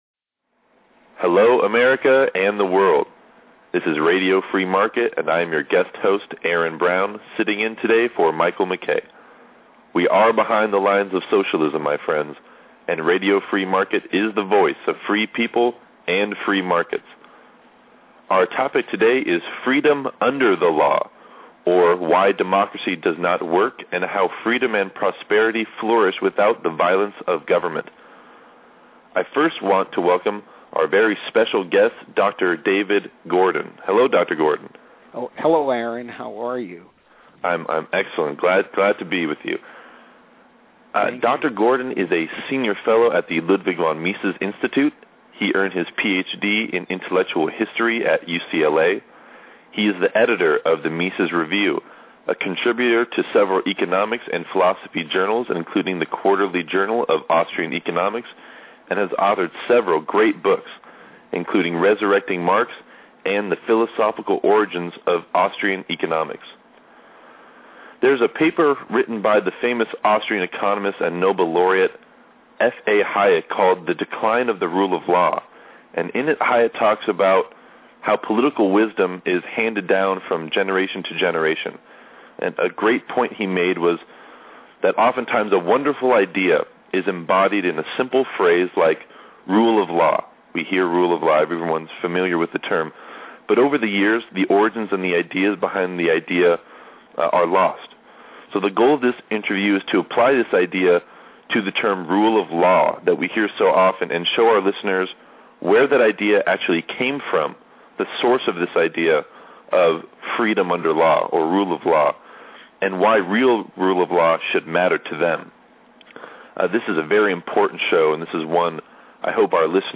** LIBERTY DEFINED: It is Time to Re-think Everything!! A BLOCKBUSTER INTERVIEW SHOW with Ron Paul and Lew Rockwell **
We are very fortunate to have these two Titans of Freedom to review and discuss Dr. Ron Paul’s new book, Liberty Defined: 50 Issues That Affect Our Freedom.